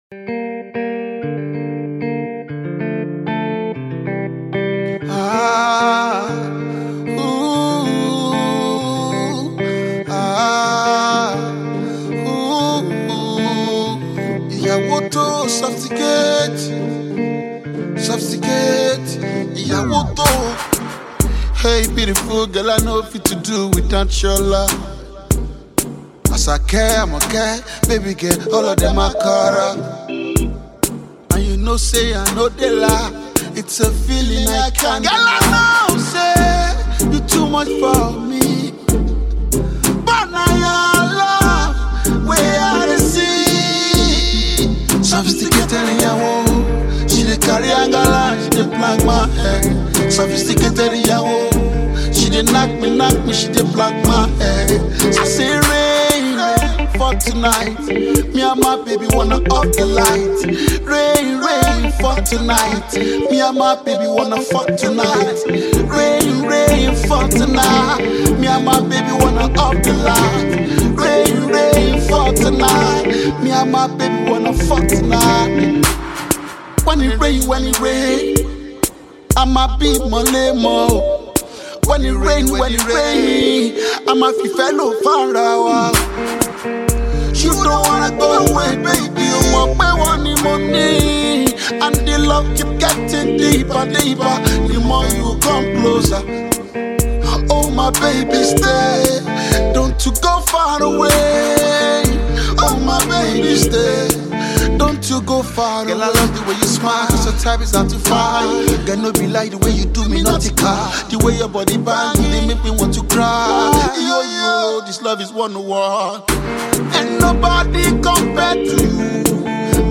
rapping and singing duo